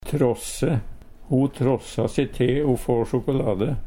DIALEKTORD PÅ NORMERT NORSK tråsse trasse Infinitiv Presens Preteritum Perfektum å trosse trossa trossa trossa Eksempel på bruk Ho tråssa se te o få sjokolade.